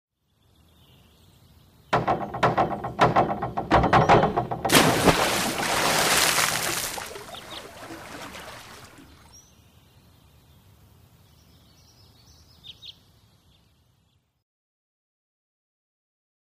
Pool, Diving | Sneak On The Lot
Several Impacts On Bouncing Diving Board With Splash Into Pool, Light Birds In Background.